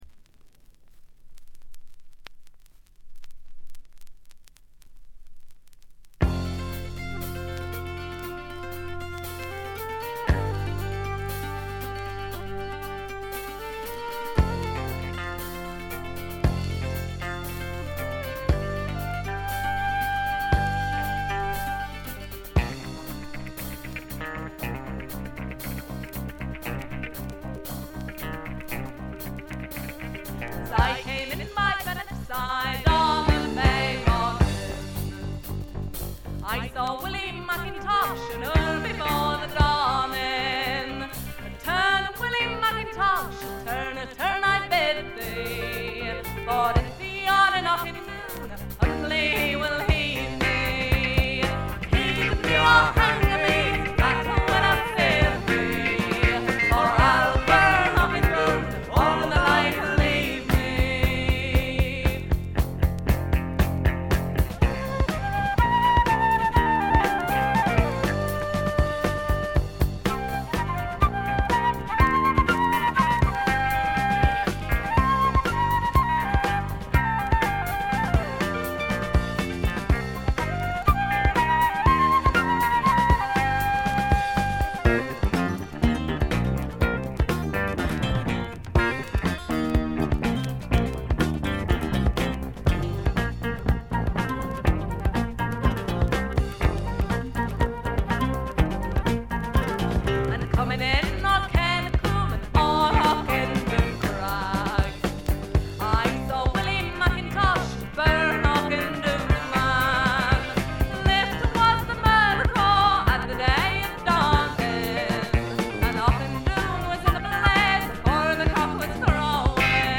静音部で軽微なチリプチが聴かれますが鑑賞に影響するようなノイズはありません。
ドラムとベースがびしばし決まるウルトラグレートなフォーク・ロックです。
フルート奏者がメンバーにいるのがこの人たちの強みですね。
試聴曲は現品からの取り込み音源です。